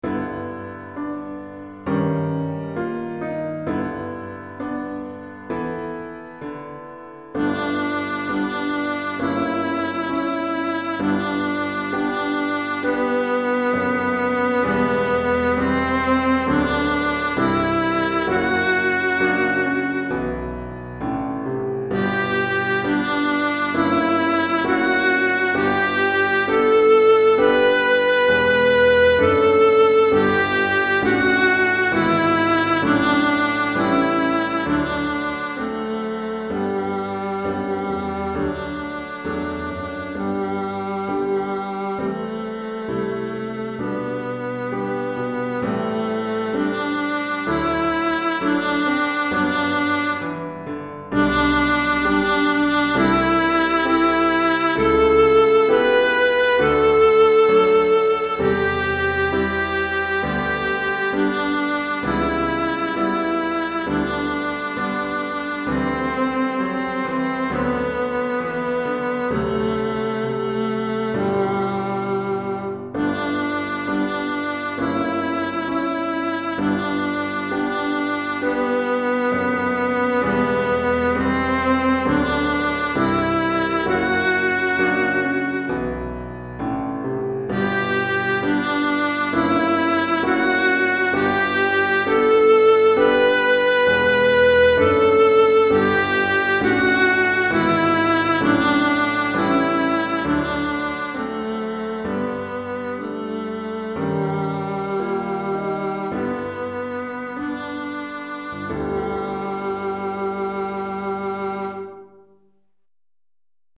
pour alto et piano DEGRE CYCLE 1